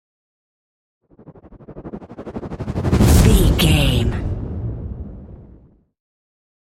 Whoosh to hit trailer long
Sound Effects
dark
futuristic
intense
tension
the trailer effect